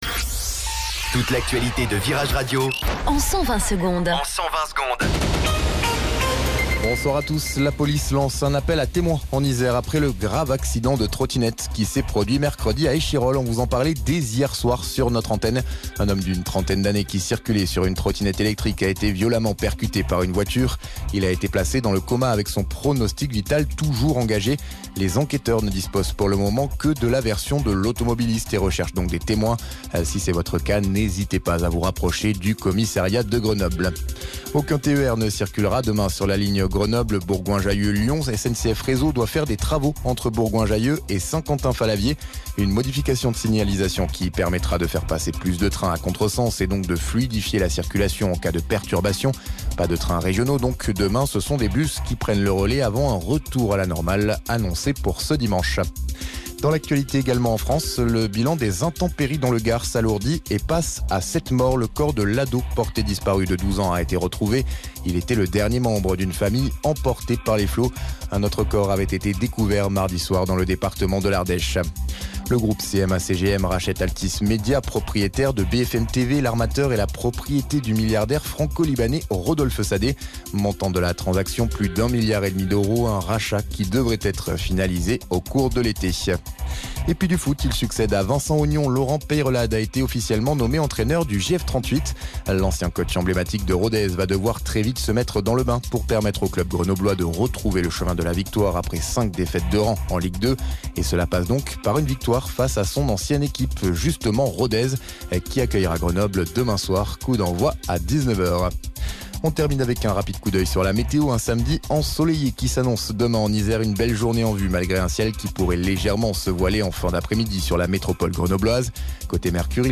Flash Info Grenoble 15 Mars 2024 Du 15/03/2024 à 17h10 Flash Info Télécharger le podcast Partager : À découvrir Oasis à la rescousse de New Order ?